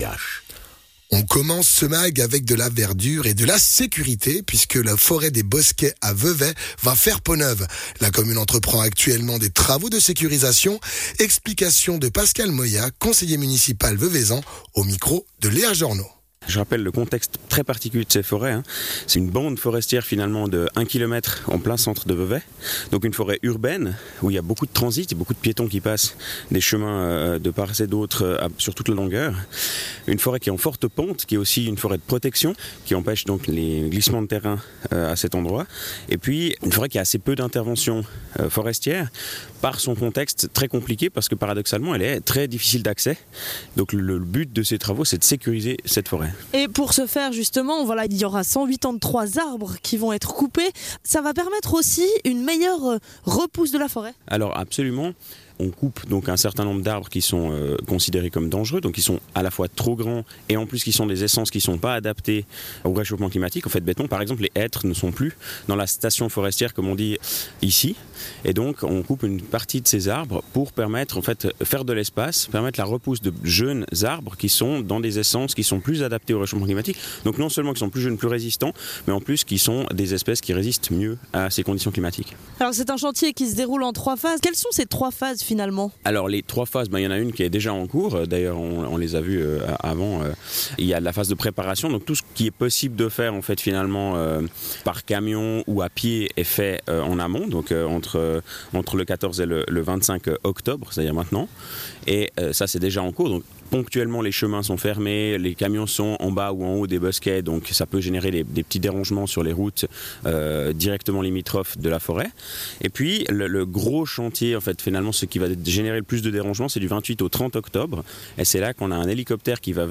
Intervenant(e) : Pascal Molliat, conseiller municipal en charge des bâtiments, gérance et énergie